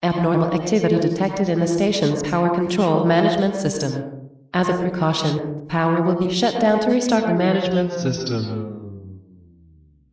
poweroff.ogg